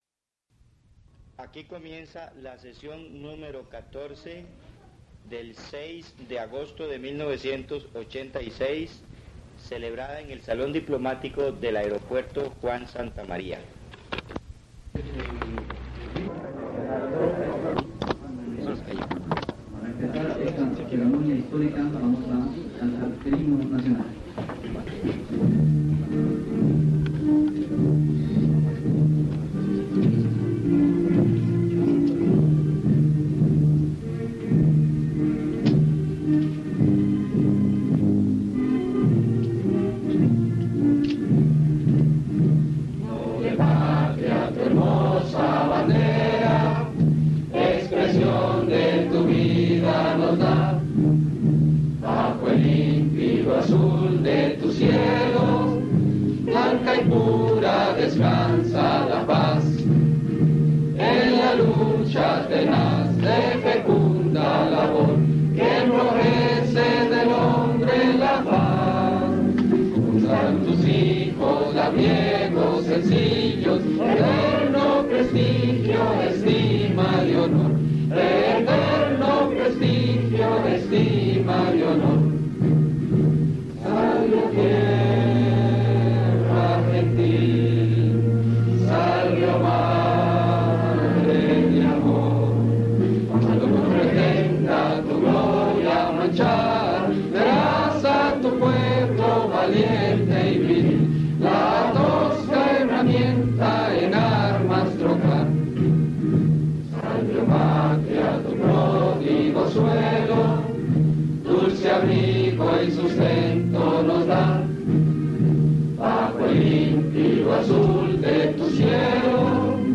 Grabación de Consejo de Gobierno, Administración Arias Sánchez. Sesión ordinaria No.14 en la que Victoria Garrón asume la Presidencia - Archivo Nacional de Costa Rica